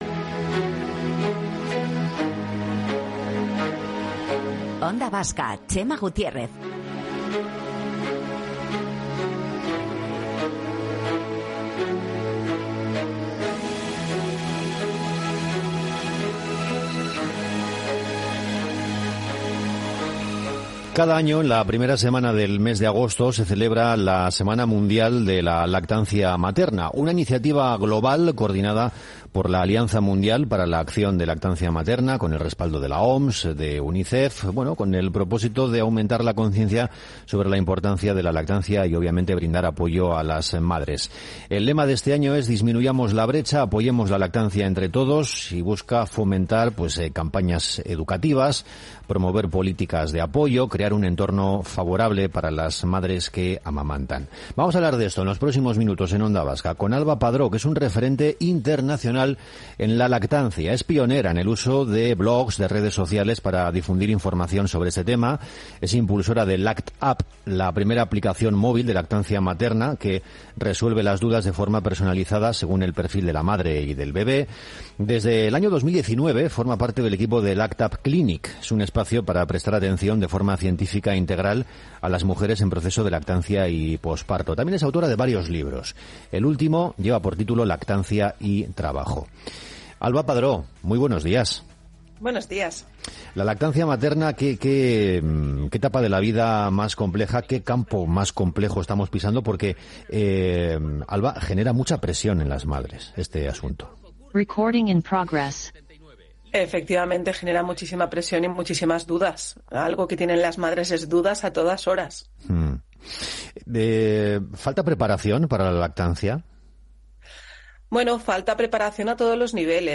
Onda Vasca Bizkaia en directo
Morning show conectado a la calle y omnipresente en la red.